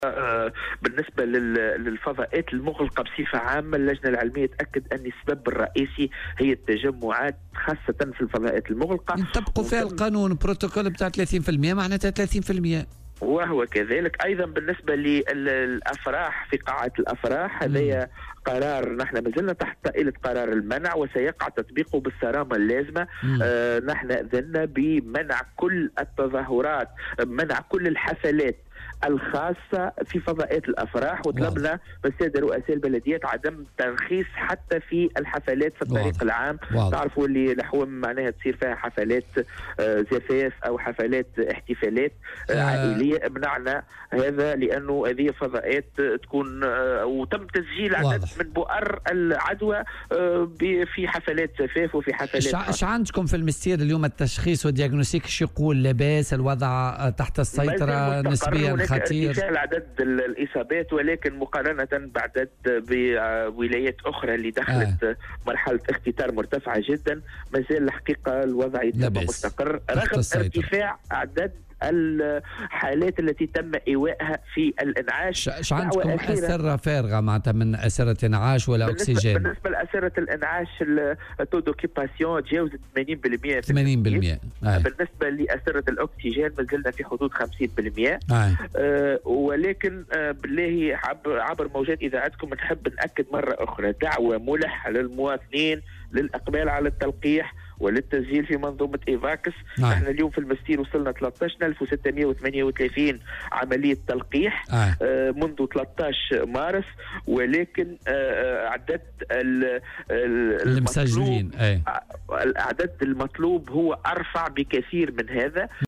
وأضاف في مداخلة له اليوم في برنامج "بوليتيكا" أن الوضع الوبائي في الجهة يعتبر مستقرا على الرغم من ارتفاع عدد الحالات التي تم نقلها للإنعاش.